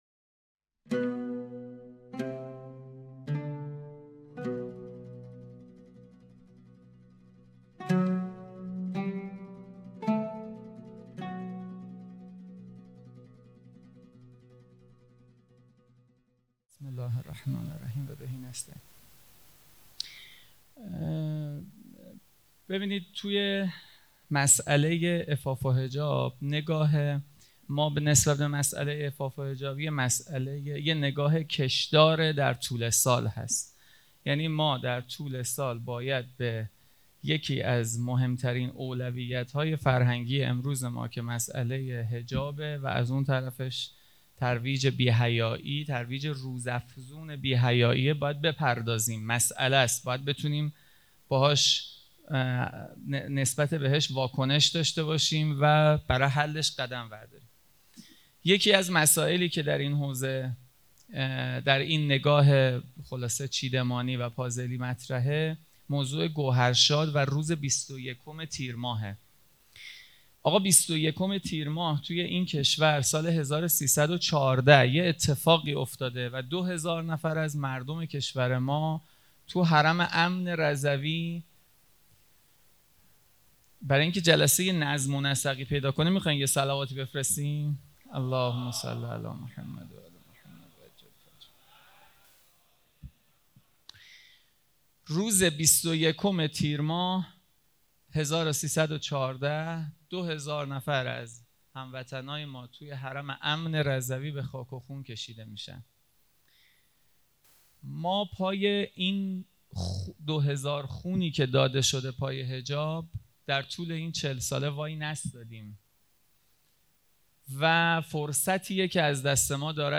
سومین اجلاس ملی رابطان جامعه ایمانی مشعر